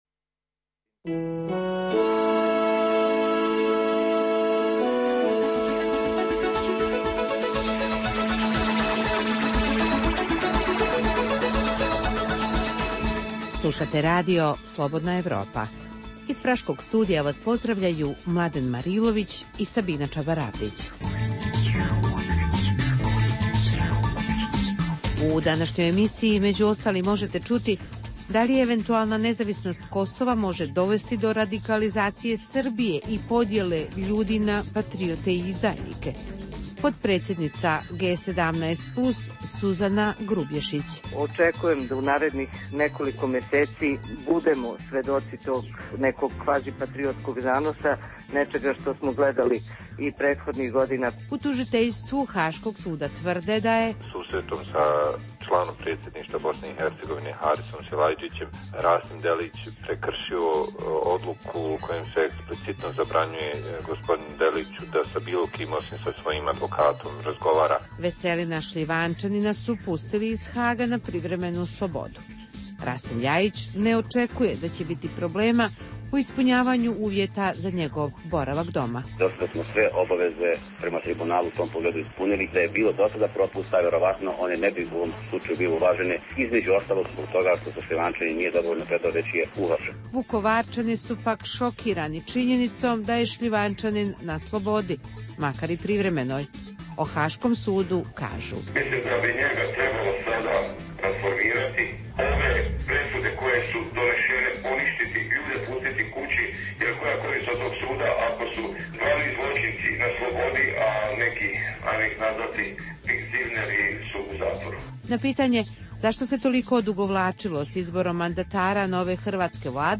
U regionalnom izdanju programa Radija Slobodna Evropa danas govorimo o radikalizaciji Srbije na kosovskom pitanju. Tražimo i odgovore na pitanja da li je penzionisani general Armije BiH Rasim Delić prekršio pravila Haškog tribunala te što se može očekivati od nove hrvatske vlade. U Dokumentima dana možete poslušati interview s Nikolom Špirićem, analizu raslojavanja vodećih političkih stranaka u BiH, te temu o tome zašto desne stranke u Srbiji nude Rusiji prostor za trajne vojne baze.